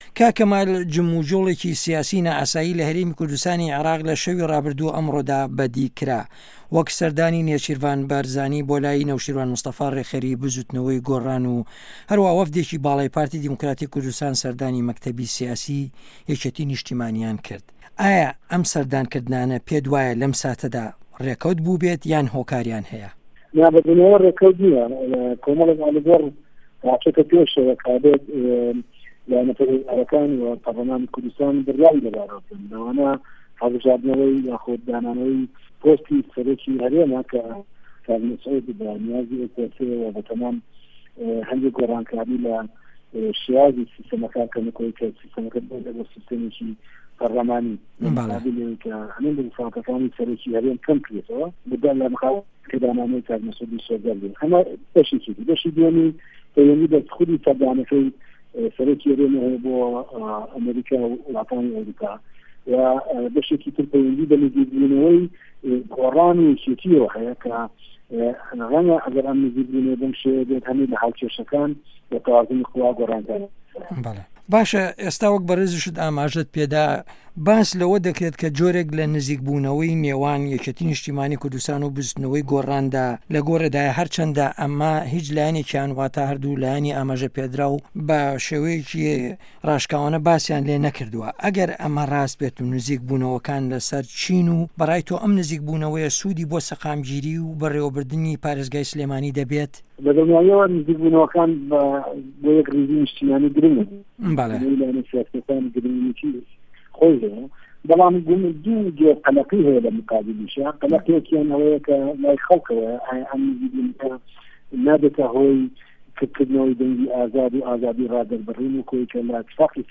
تووێژ